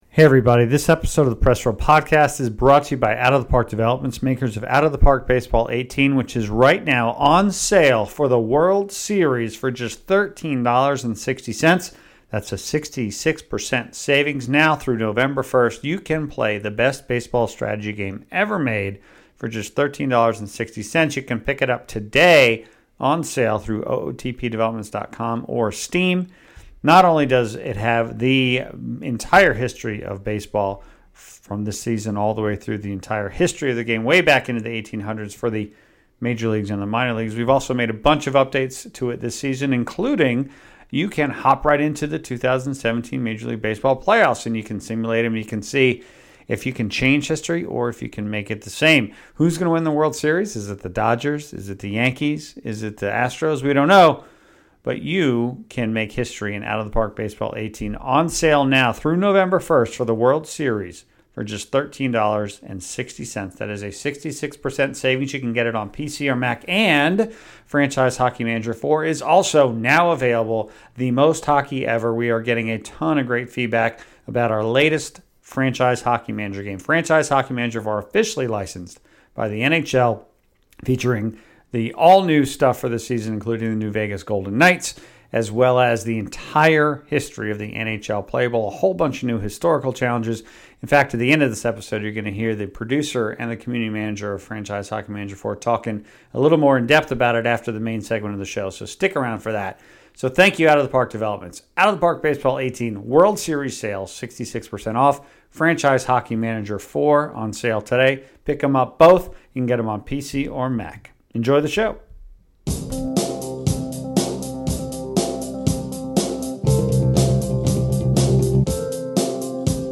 What is it about these team building, card collecting modes that have proven to be so compelling and successful? What challenges do they face right now and into the future? A panel of experts on the various modes throughout the genre discuss the phenomenon on this episode.